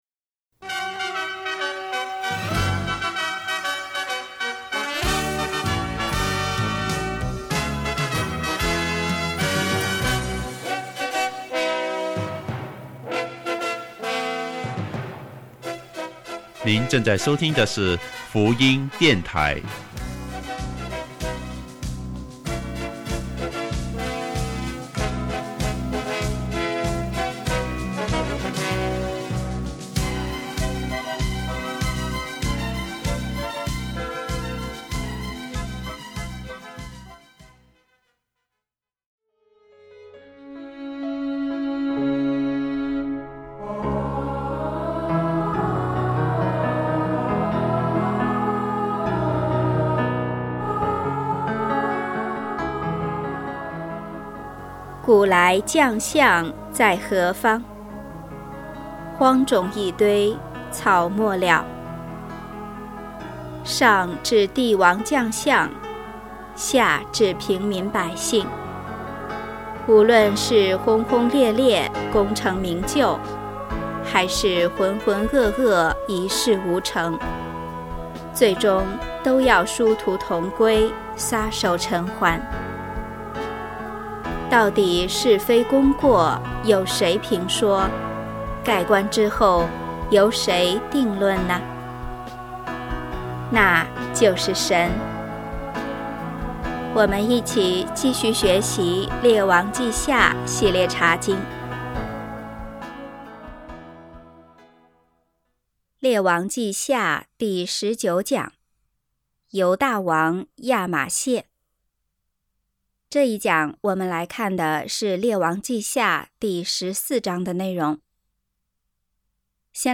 信息